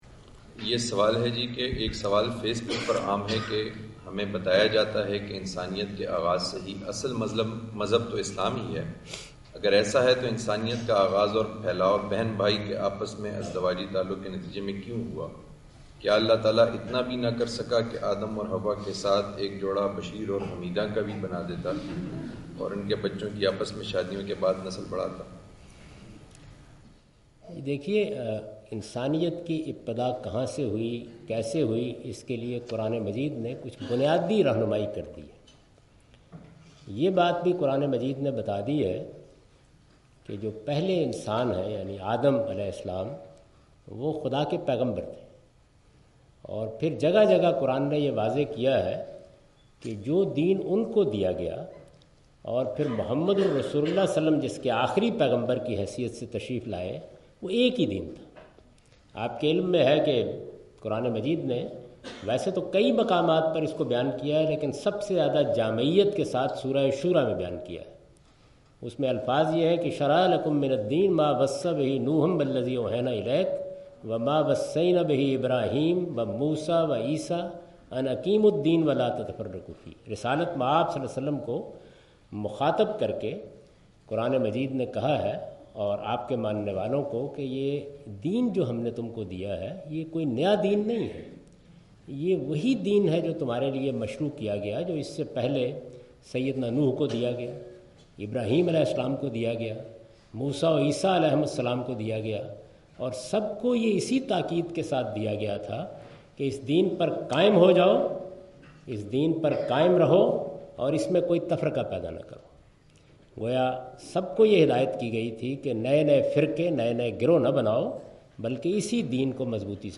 Javed Ahmad Ghamidi answer the question about "why Allah expanded human population through siblings?" during his Visit of Brunel University London in March 12, 2016.